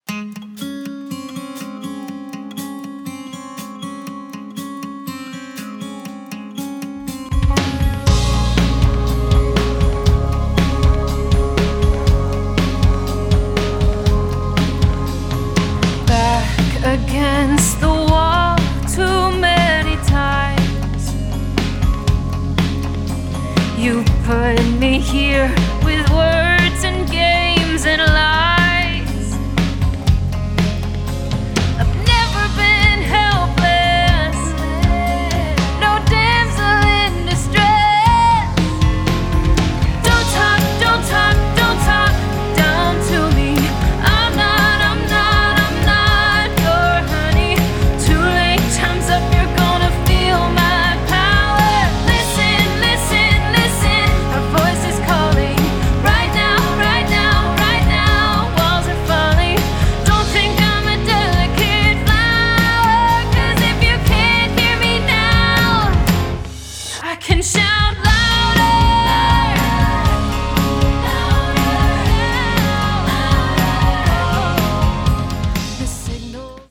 backed once again by the top Nashville studio musicians.
empowering anthems